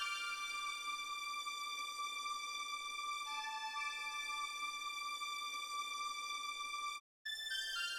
ITA String Riff A-D.wav